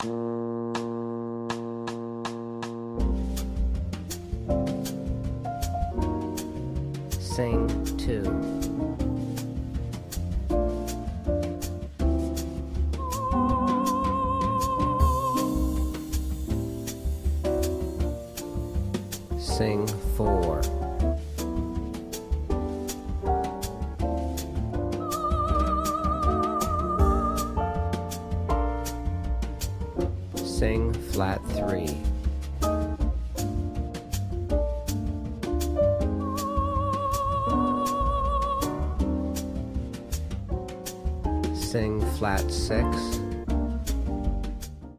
• Singing Exercise with Soprano Voice